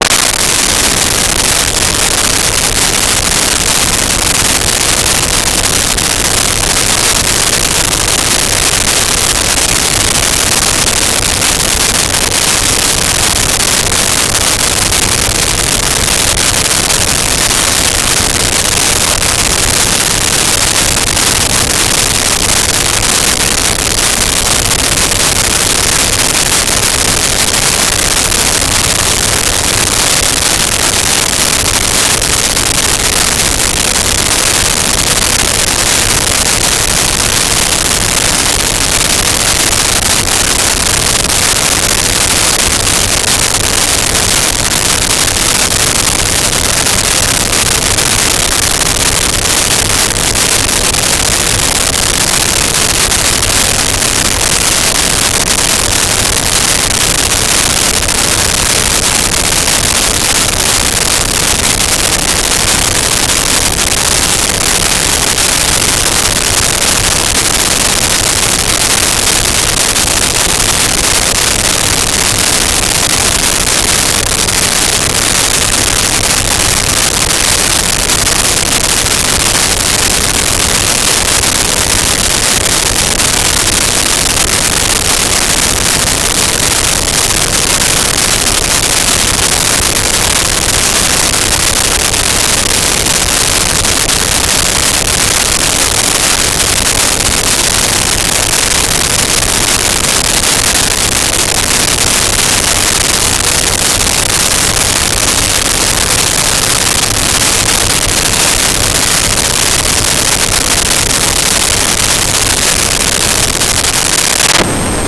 Train Diesel Idle
Train Diesel Idle.wav